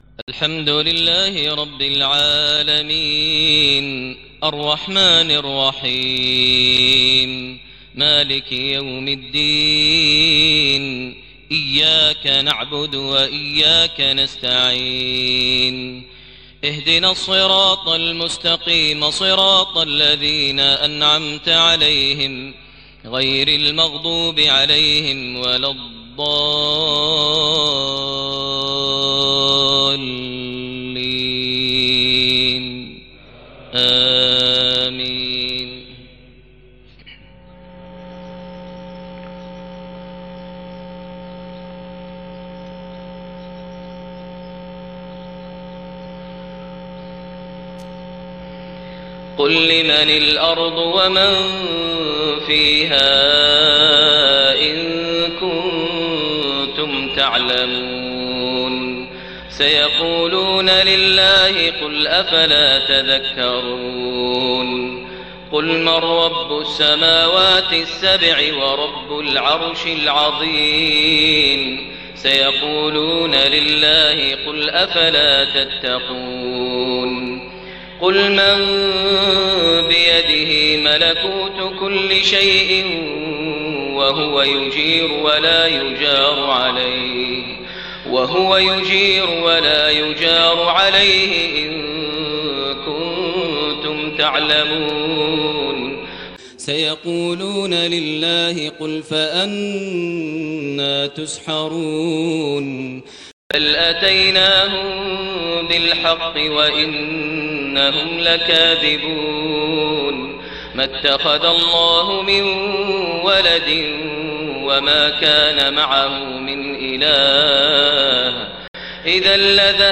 11/03/2009 > 1430 H > Prayers - Maher Almuaiqly Recitations